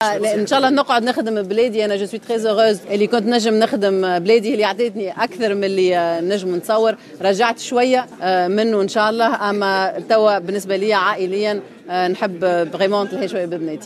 وأضافت كربول على هامش ندوة صحفية عقدتها وزارة السياحة اليوم الخميس حول حصيلة القطاع السياحي لسنة 2014 أنها تفضلّ التفرّغ لعائلتها.